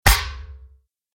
دانلود آهنگ دعوا 31 از افکت صوتی انسان و موجودات زنده
دانلود صدای دعوا 31 از ساعد نیوز با لینک مستقیم و کیفیت بالا
جلوه های صوتی